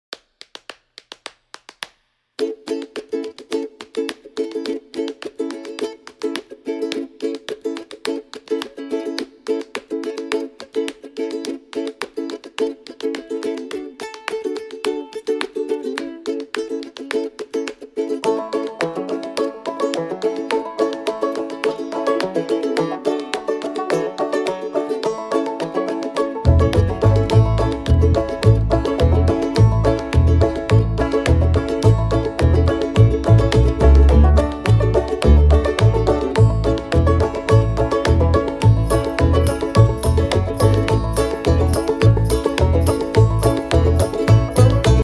Listen to a sample of the instrumental track..